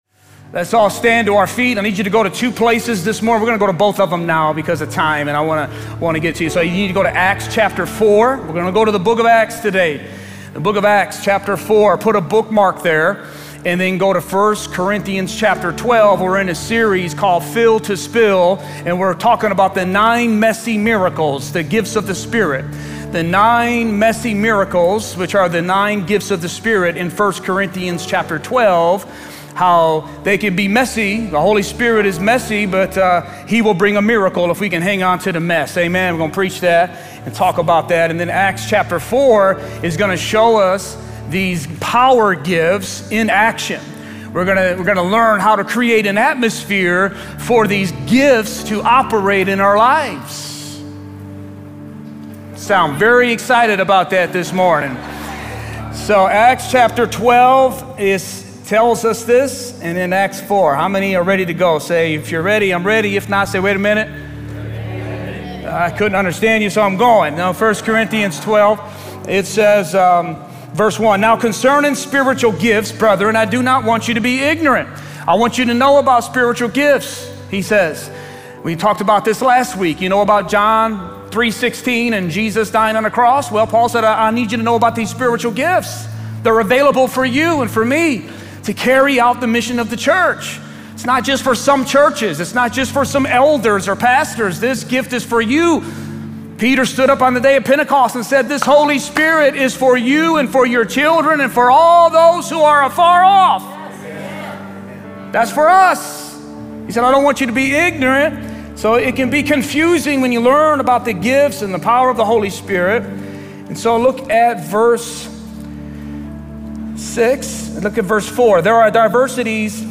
Sermons | River of Life Church